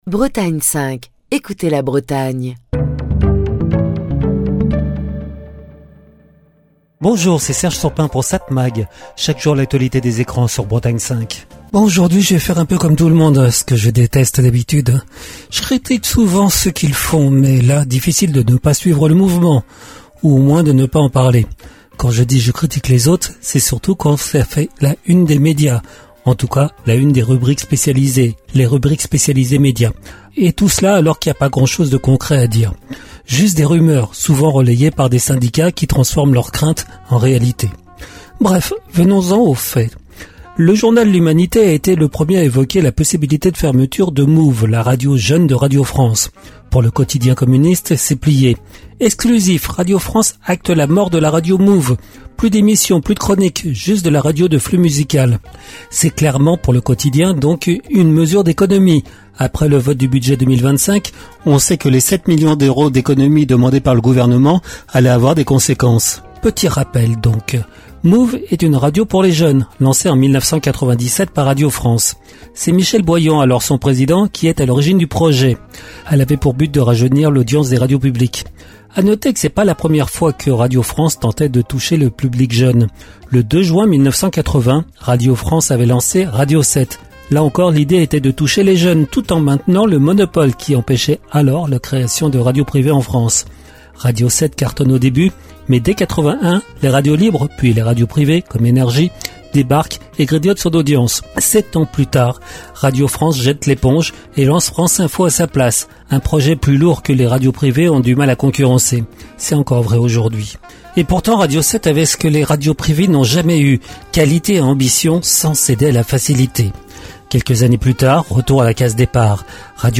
Chronique du 21 avril 2025.